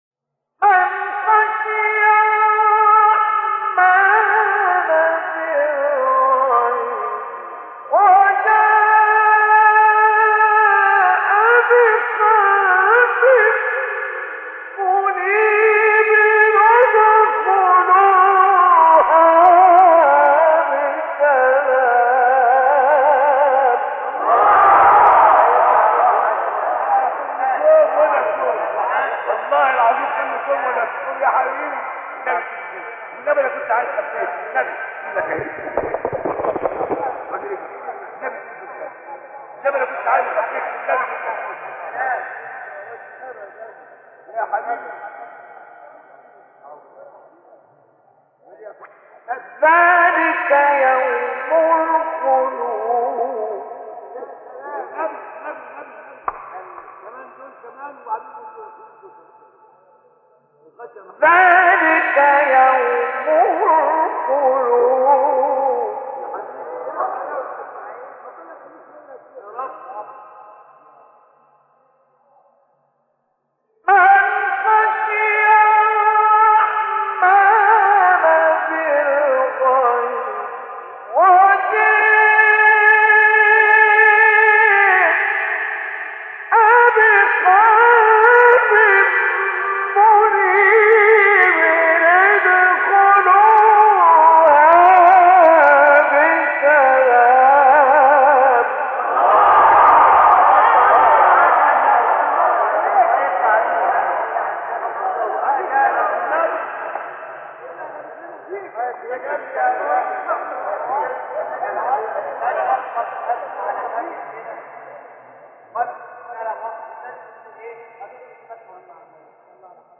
آیه 33-34 سوره ق استاد مصطفی اسماعیل | نغمات قرآن | دانلود تلاوت قرآن